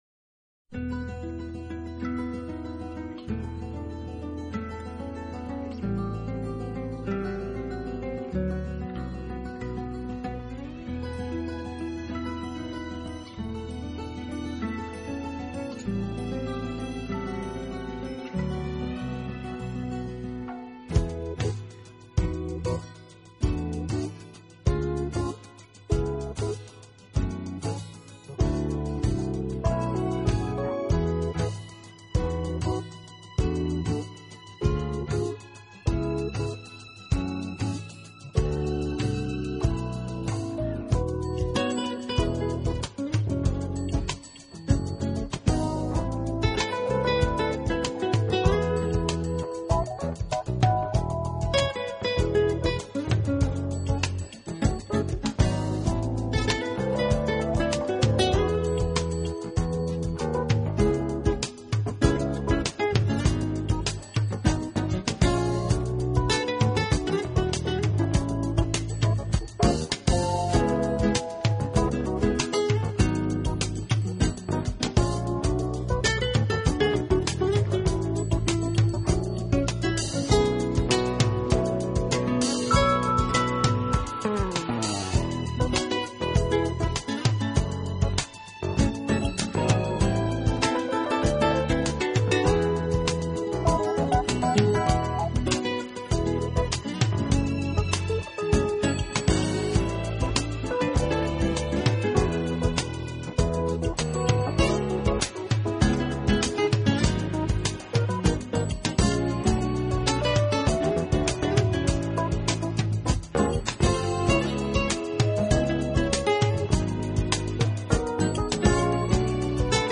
Fusion经典录音 20年销路不衰 XRCD版本音效更佳。
节奏感强烈与劲道十足的指法是其特色，最难能可贵是整体合作有极高的默契，每位大师都能毫无保留的演出。
他，演奏富有拉丁浪漫色彩的音乐，流畅轻快的流行音乐节奏与充满异国风情的拉丁音乐